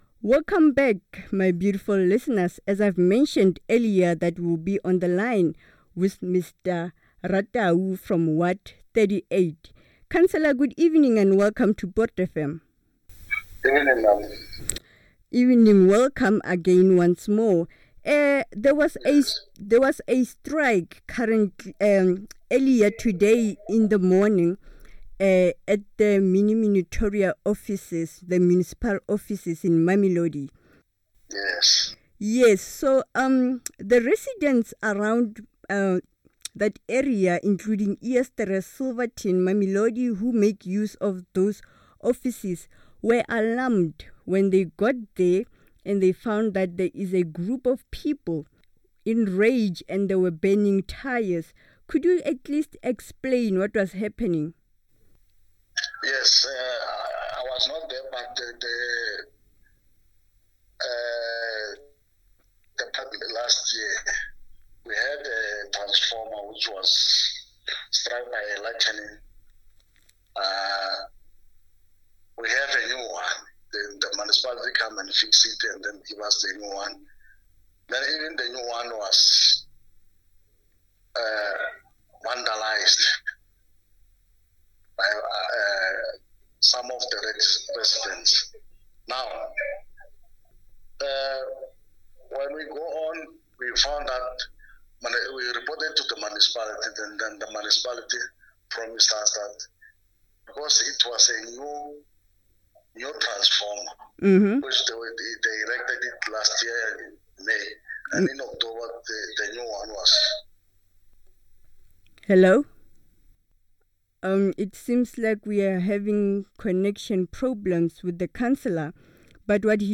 Say No to E-Hailing Transport Interview on Poortfm
No-to-E-Hailing-Transport-Interview.mp3